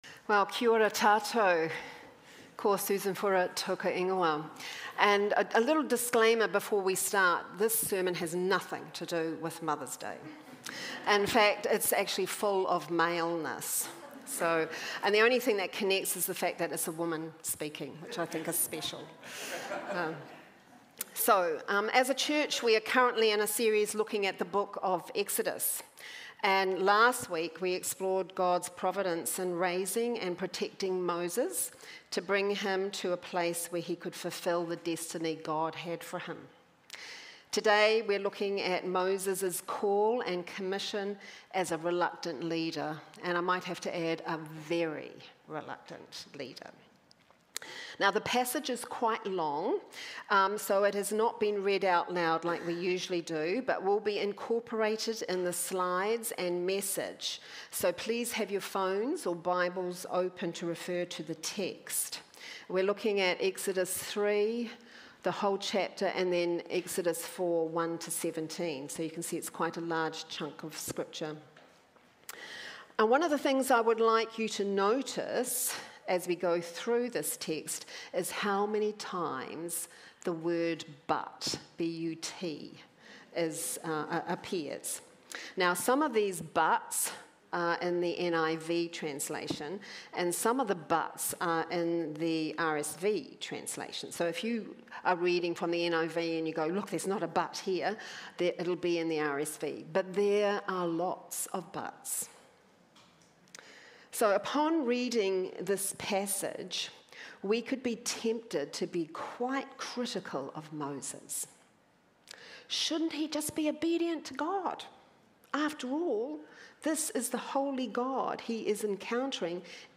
Sermons | Titirangi Baptist Church